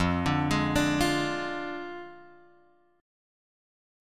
F Minor 6th
Fm6 chord {1 x 0 1 3 1} chord